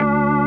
Ну или вот семпл с железки Вложения ODD ORG.D4.wav ODD ORG.D4.wav 41,5 KB · Просмотры: 230